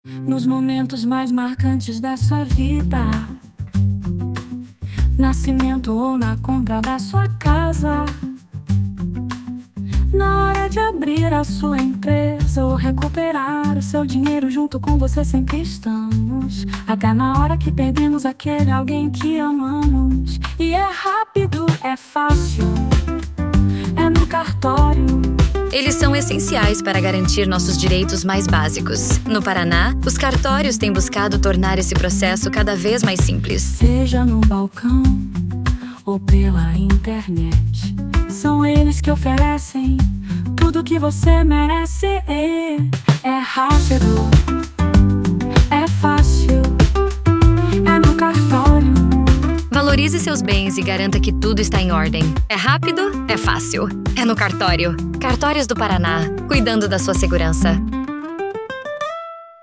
Jingle
Jingle 1 minuto